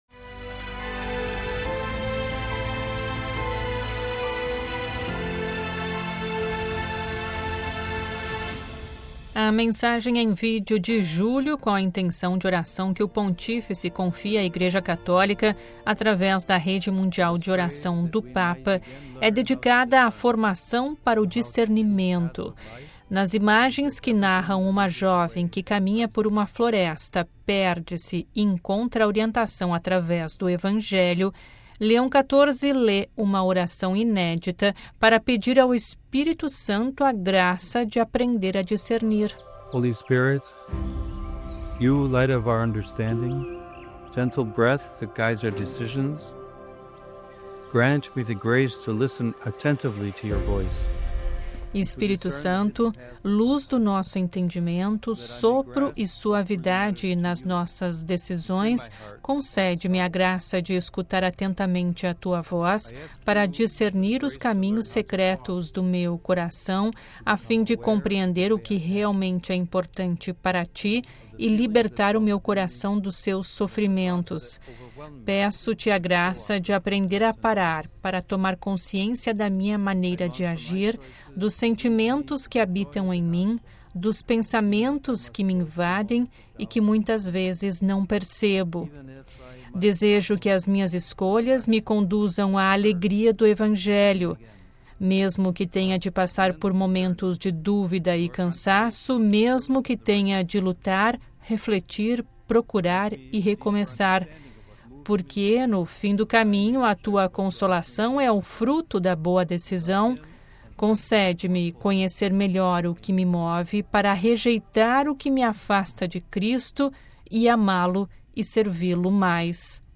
Ouça a reportagem com a voz do Papa e compartilhe